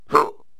stickfighter_attack11.wav